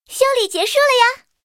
SU-26修理完成提醒语音.OGG